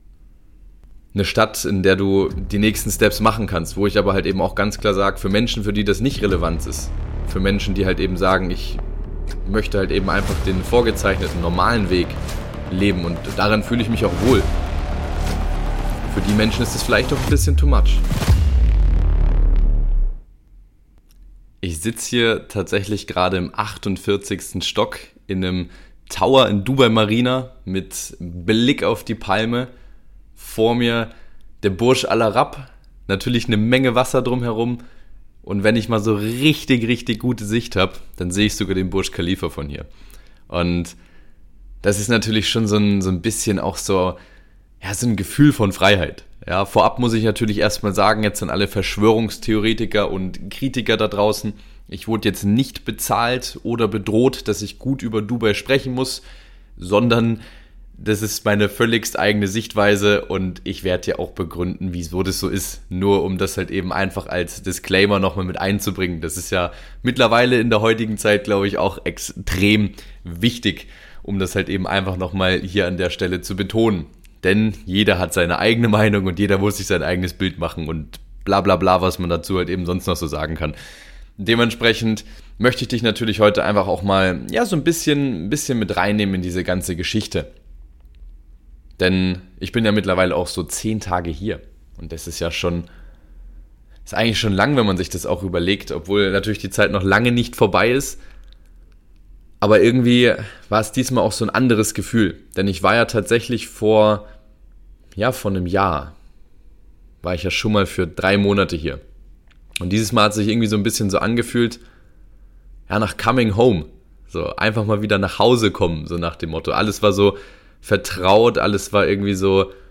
Dubai sorgt immer wieder fürs Gesprächsstoff. Die ersten Tage live vor Ort.